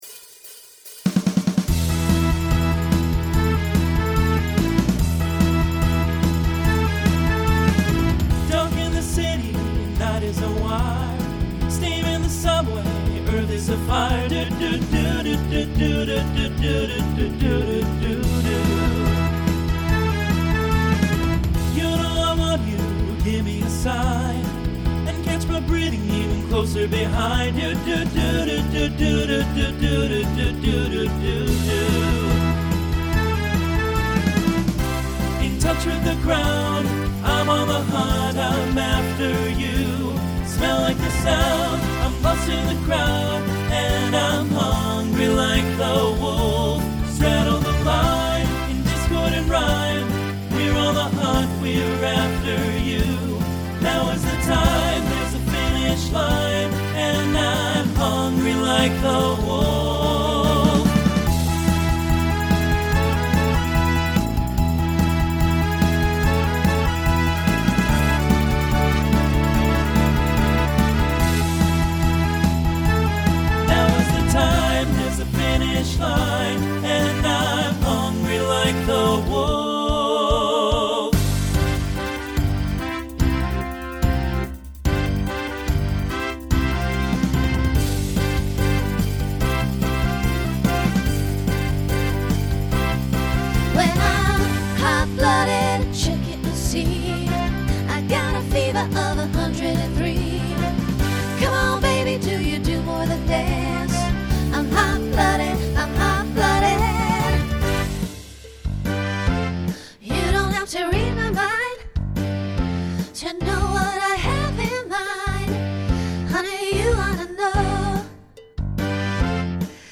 Genre Rock Instrumental combo
Transition Voicing Mixed